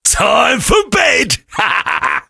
sam_kill_vo_05.ogg